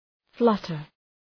Shkrimi fonetik {‘flʌtər}